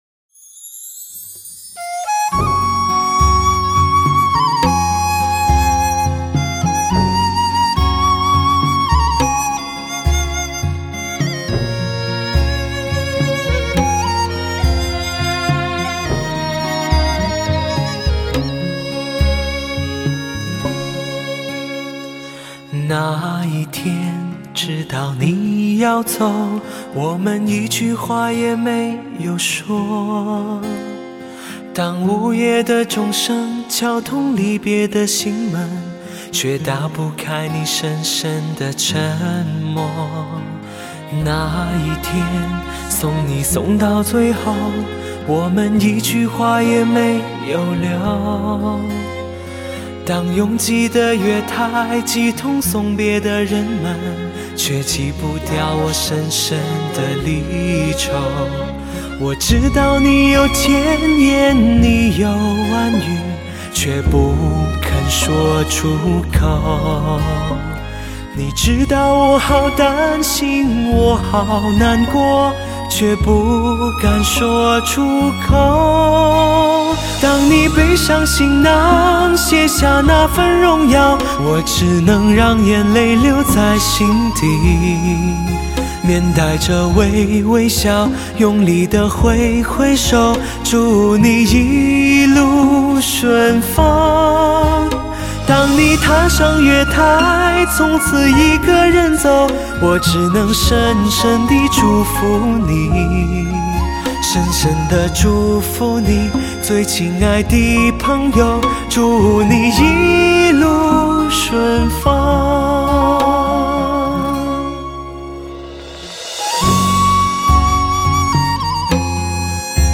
不食人间烟火的梦幻男声——倾魂深处。
天生唯美的金嗓，绝具绕梁三日的功力。
气音带出的尾韵，堪称“楚楚动人”，着实无比地让人倾魂！
最佳勾魂金嗓与最佳经典之曲的美丽邂逅；细细聆赏，在这里的音乐节奏特质却随着HI-FI高品质的移转而更合乎当代的时尚。